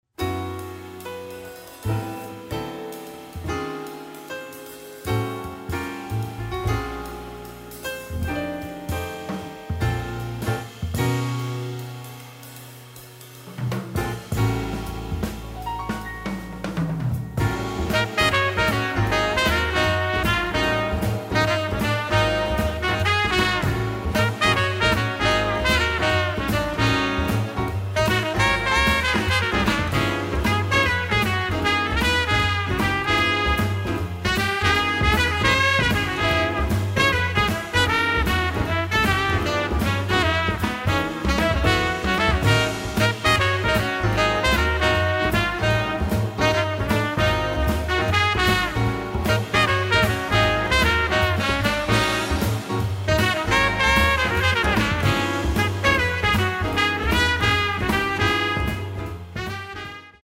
trumpet, keyboards